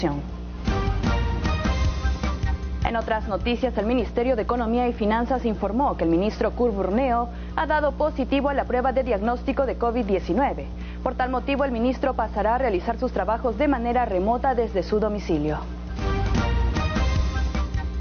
Microinformativo - Exitosa Tv